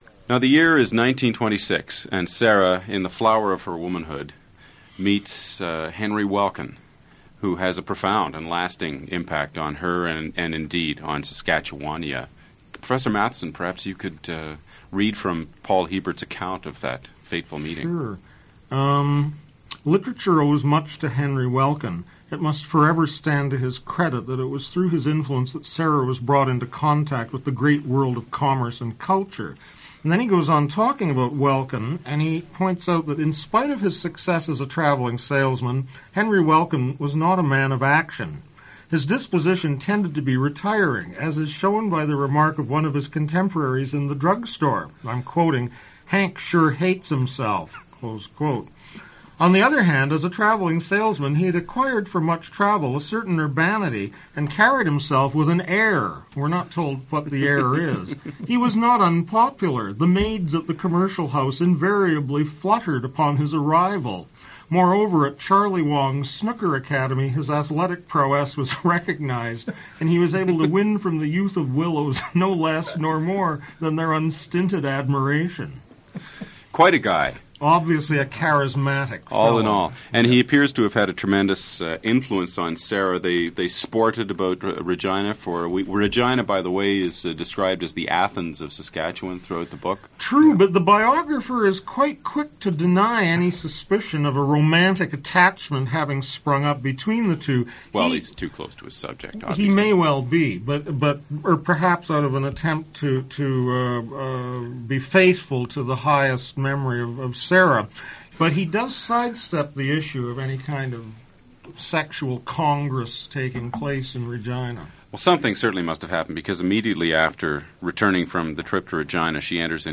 Hiebert, Paul, 1892-1987Canadian literature--20th centurySatire, CanadianRadioInterviewing in journalism